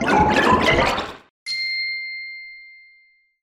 Pick Map Door Sound Effect
pick-map-door.mp3